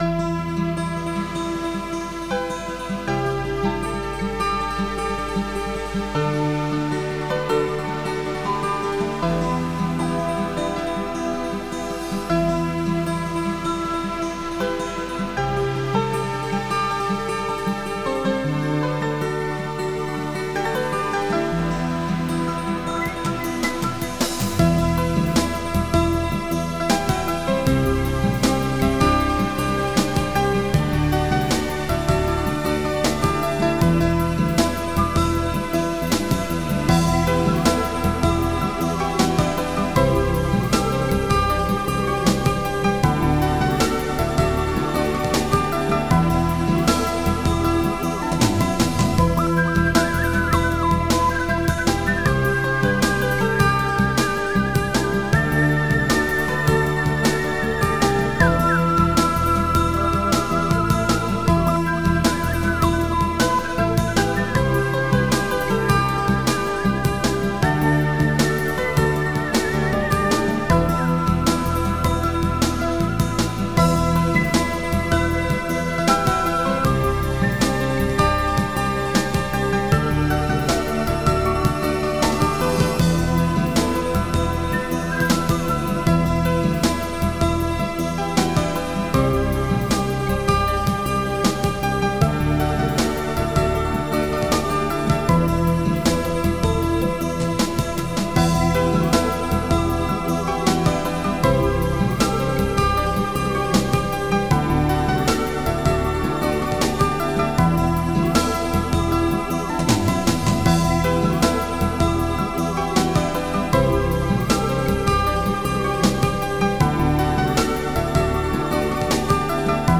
Extended Module
Holliday Postcard Type xm (FastTracker 2 v1.04)
My Guitar